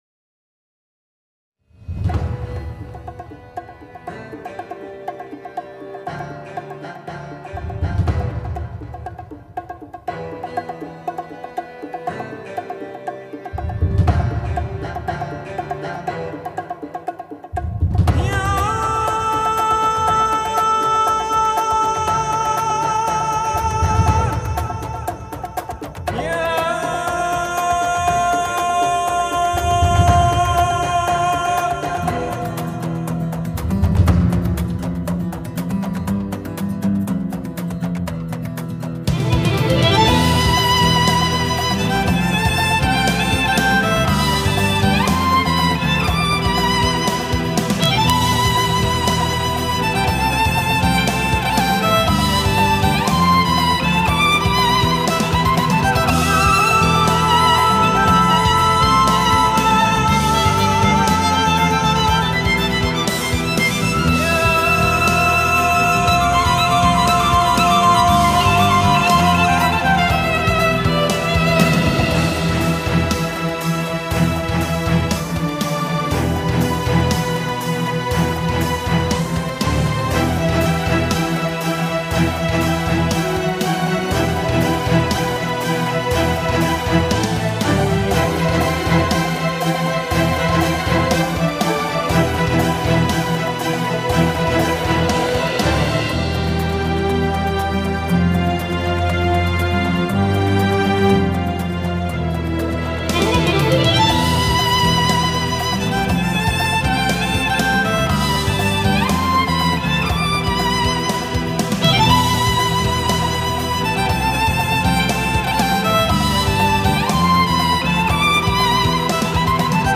tema dizi müziği, duygusal hüzünlü gerilim fon müziği.